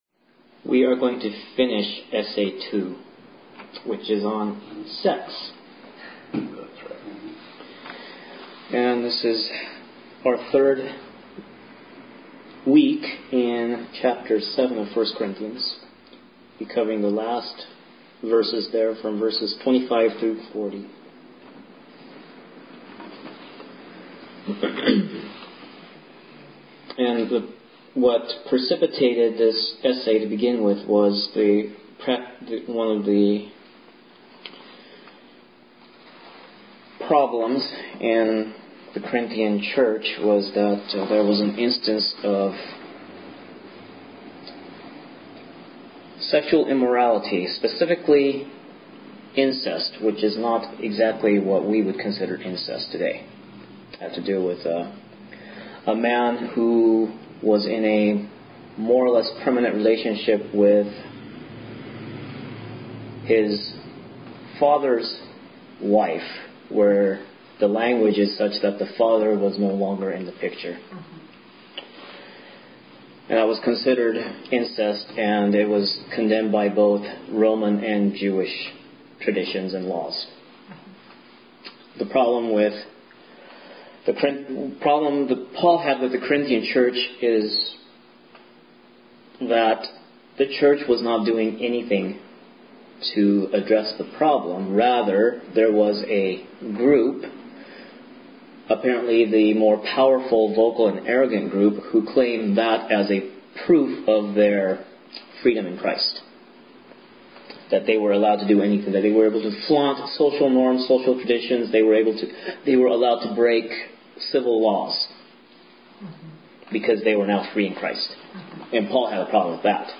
Available study guides and discussion audio recordings are found on this page.